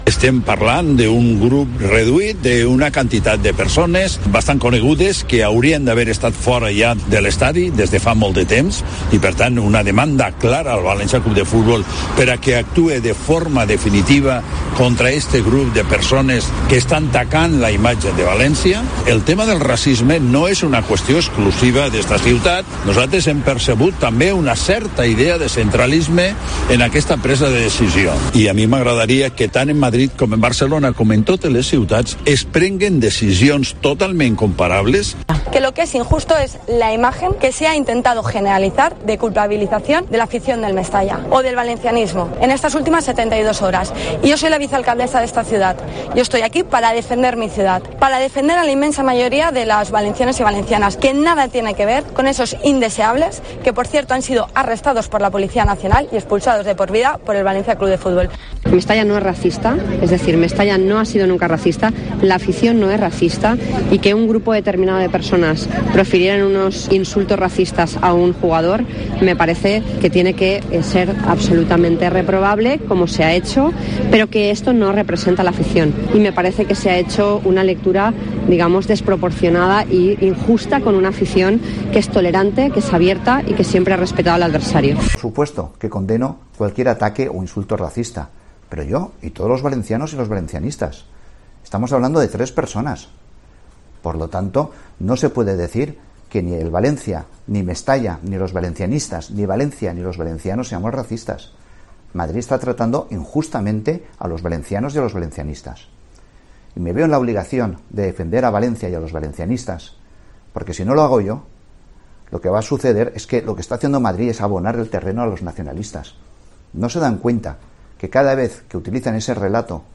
Escucha las reacciones de los portavoces en el Ayuntamiento a la sanción al Valencia C.F.
El alcalde de València, de Compromís, Joan Ribó; la vicealcaldesa de València, del PSPV, Sandra Gómez, la portavoz del PP, María José Catalá, y el portavoz de Ciudadanos, Fernando Giner, también han opinado acerca de la sanción que se le ha impuesto al Valencia C.F. por el caso de Vinicius.